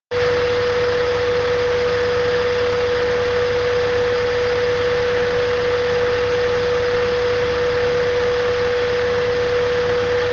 Ansauggeräusch - lautes Heulen im Leerlauf - Forum: Allgemein
Hallo zusammen, war lange nicht mehr anwesend, jetzt habe ich wieder einen del sol eh6. Eine TSS Ansaugung wurde verbaut und nach einer gewissen Zeit heult es im Leerlauf.